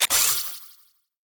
Sfx_tool_spypenguin_catchsuccess_01.ogg